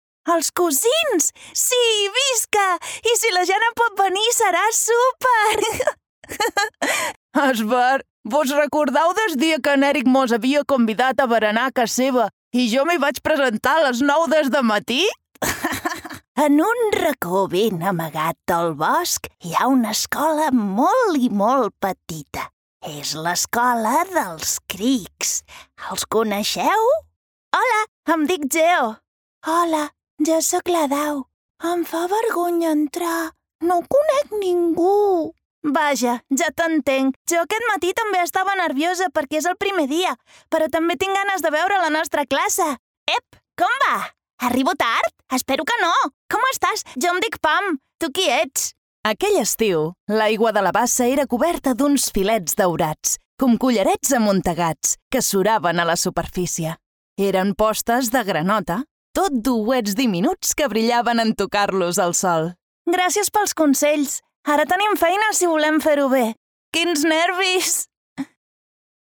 Warm, Zacht, Natuurlijk, Vriendelijk, Jong